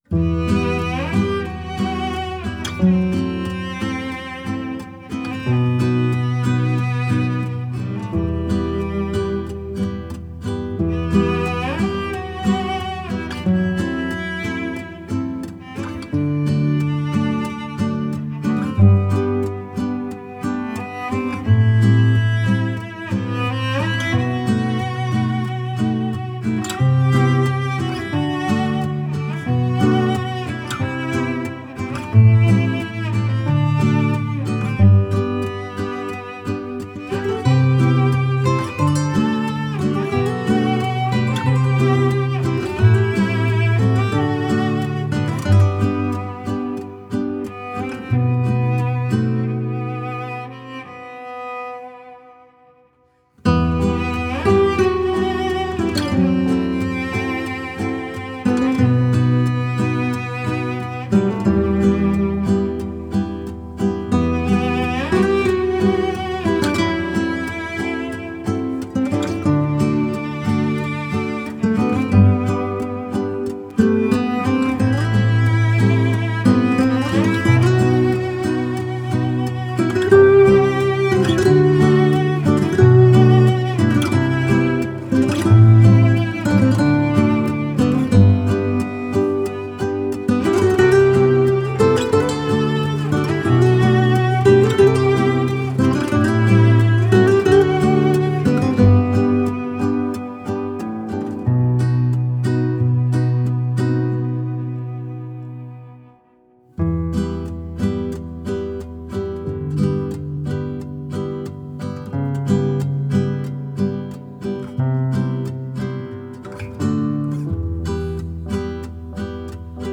tema dizi müziği, duygusal huzurlu rahatlatıcı fon müziği.